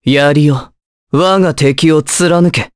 Pavel-Vox_Skill2_jp_b.wav